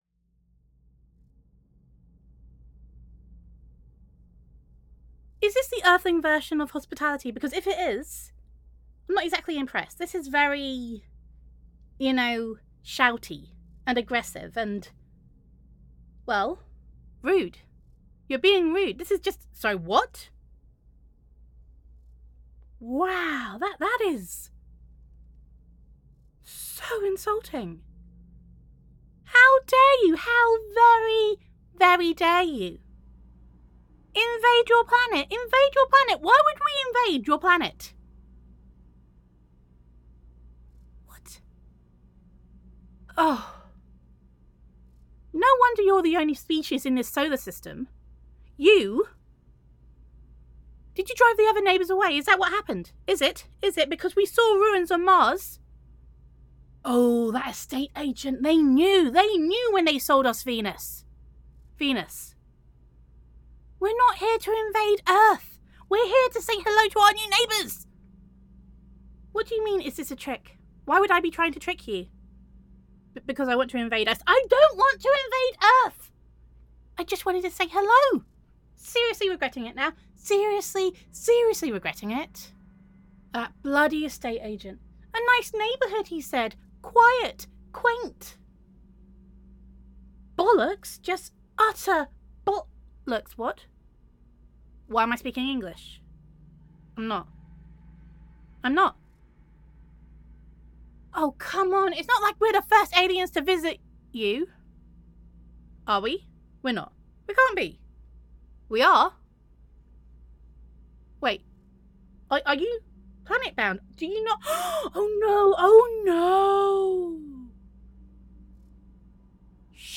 [F4A] The Good Neighbour [Alien Roleplay]